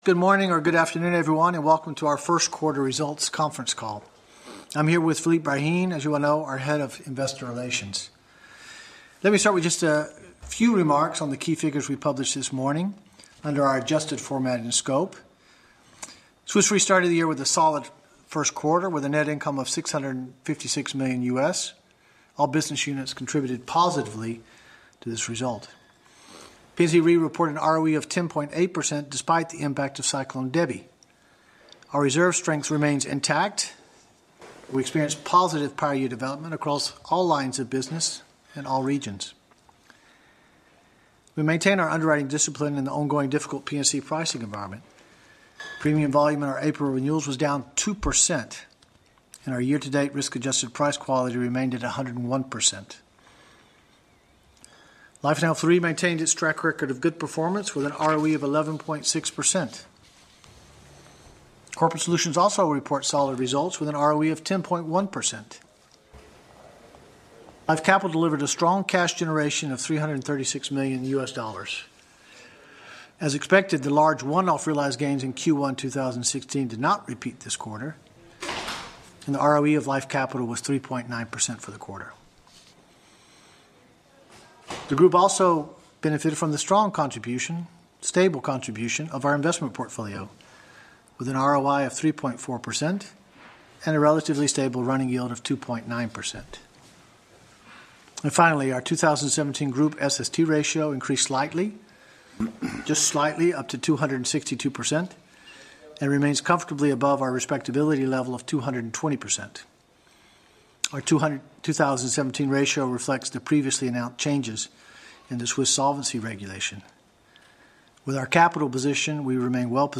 Analysts Conference call recording
2017_q1_qa_audio.mp3